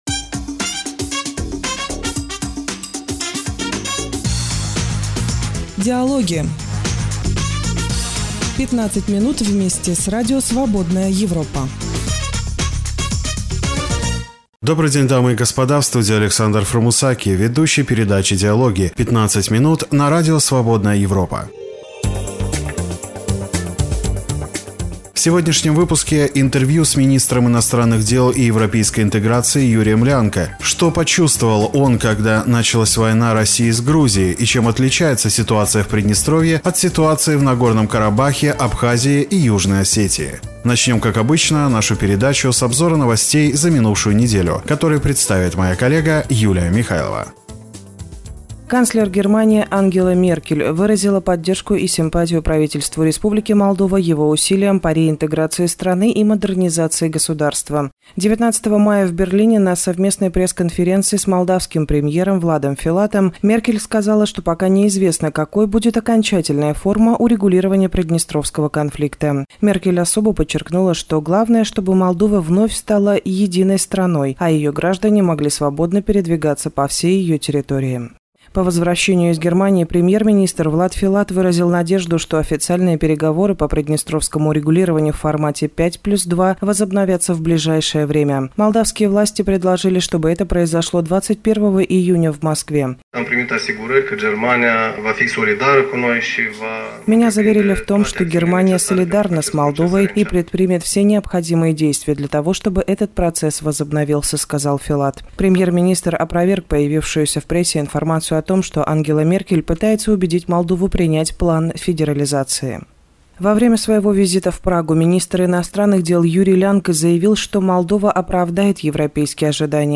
В сегодняшнем выпуске – интервью с министром иностранных дел и европейской интеграции Юрием Лянкэ. Что почувствовал он, когда началась война России с Грузией, и чем отличается ситуация в Приднестровье от ситуации в Нагорном Карабахе, Абхазии и Южной Осетии?